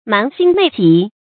瞞心昧己 注音： ㄇㄢˊ ㄒㄧㄣ ㄇㄟˋ ㄐㄧˇ 讀音讀法： 意思解釋： 昧：欺瞞。